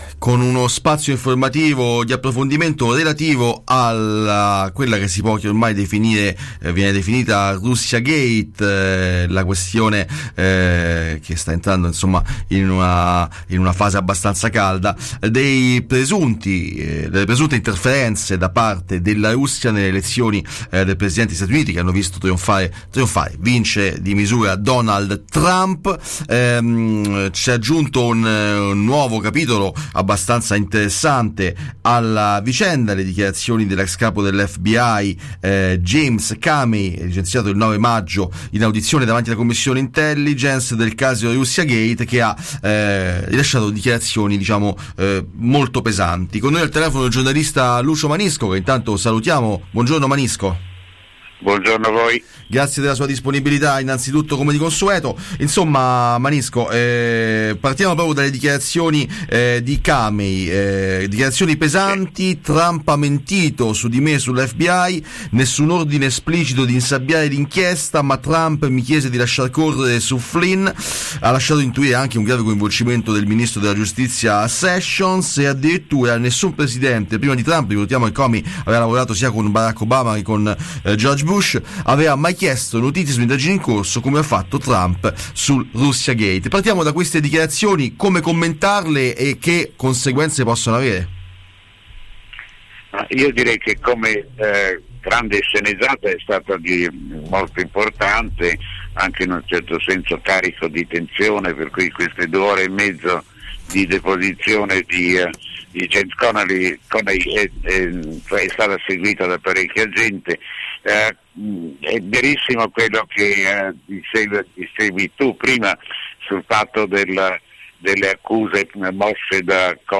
Russiagate: intervista a Lucio Manisco | Radio Città Aperta